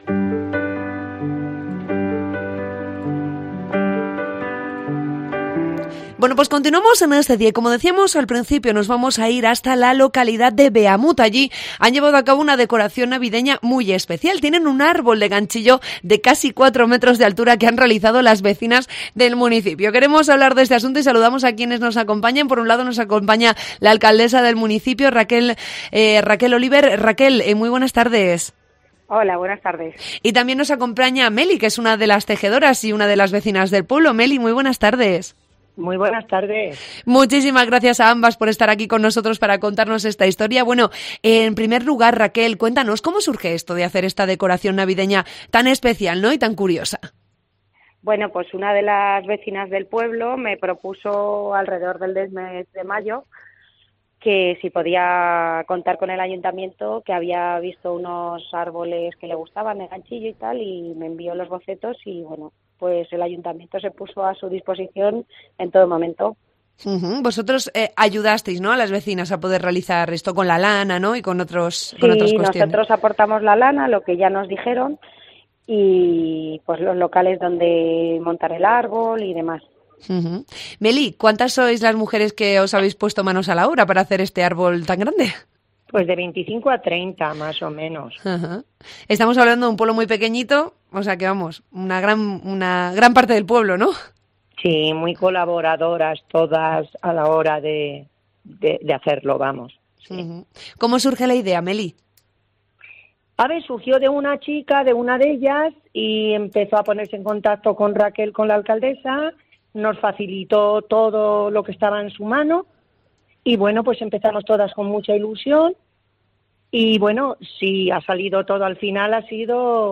Entrevista con la alcaldesa de Beamud